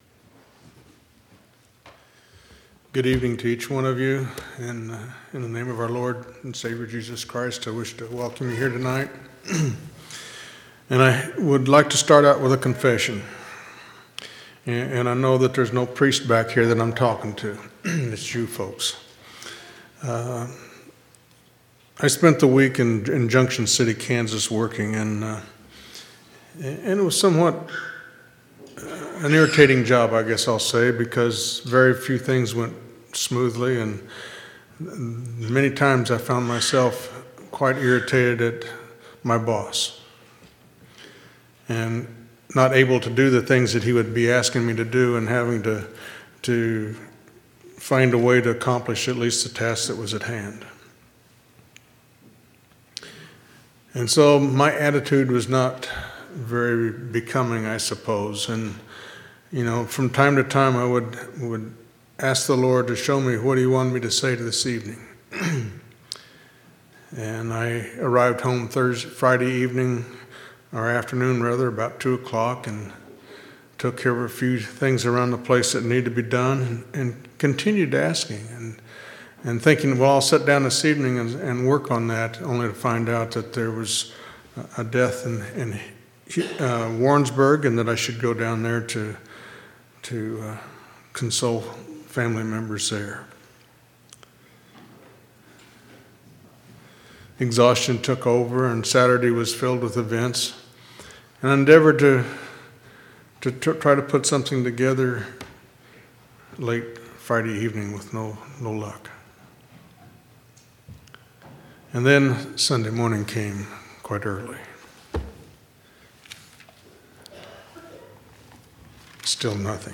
11/18/2012 Location: Temple Lot Local Event